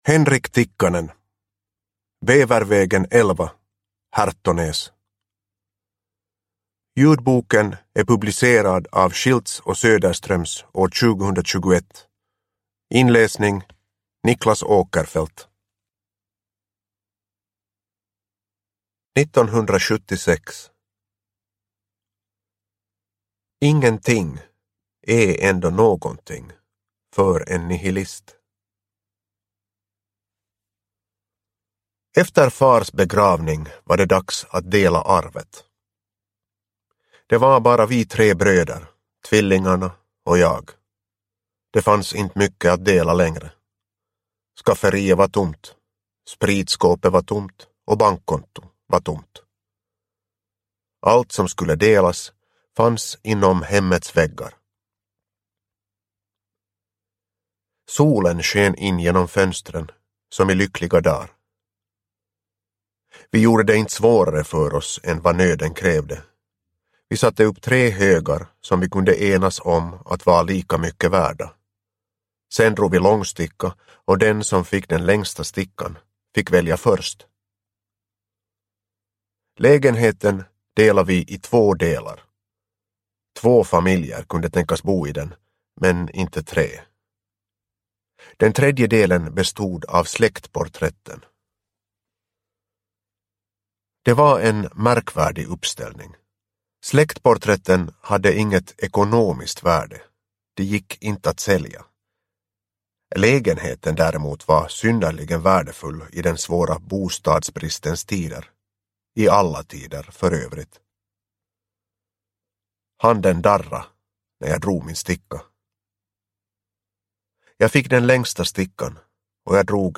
Bävervägen 11 Hertonäs – Ljudbok – Laddas ner